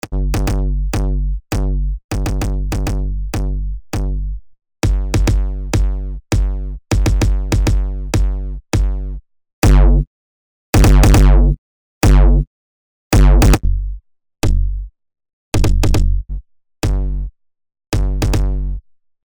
Im folgenden Audiodemo hören Sie die sechs Shape-Charakteristika nacheinander – allerdings ohne Anpassung von Preamp und Volume. Das Demo wird also dem Effekt nicht ganz gerecht, zeigt aber dennoch die verschiedenen Basis-Charakteristika.